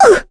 Mirianne-vox-Landing.wav